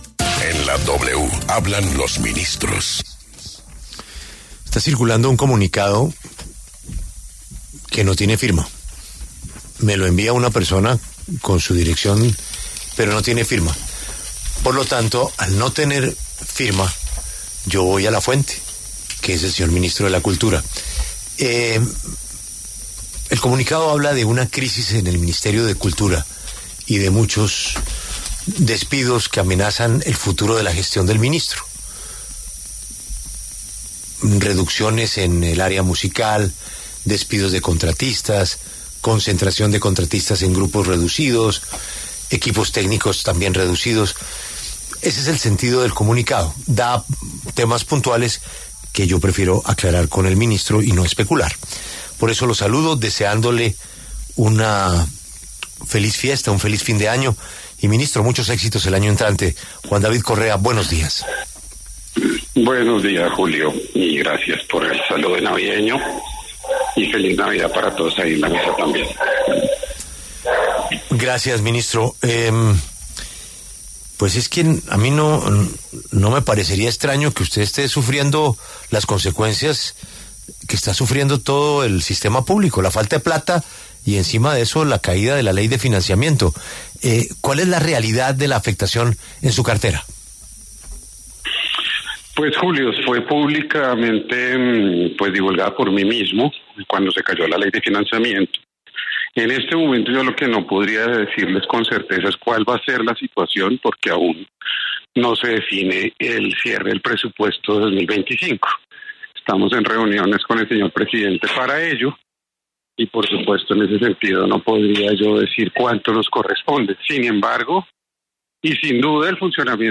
En conversación con La W, el ministro de Cultura, Juan David Correa, explicó cómo se verá afectada su cartera con el recorte presupuestal para el 2025 luego de la caída de la ley de financiamiento en el Congreso.